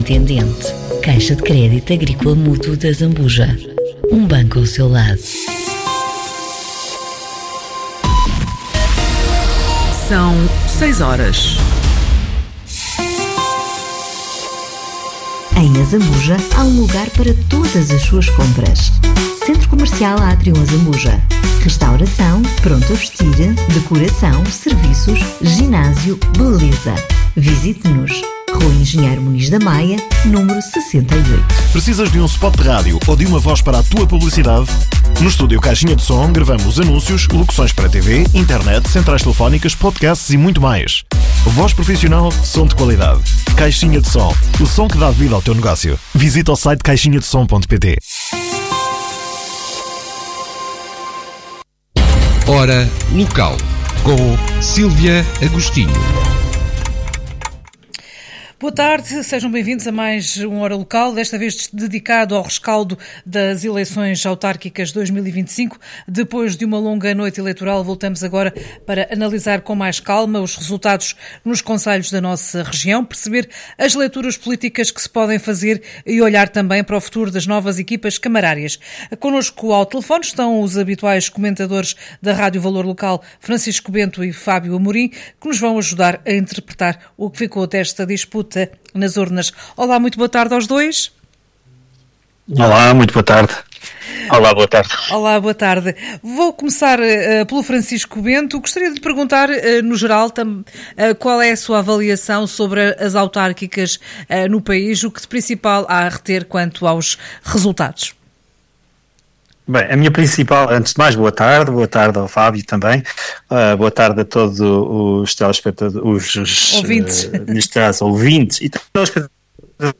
COMENTARIO-HORA-LOCAL.mp3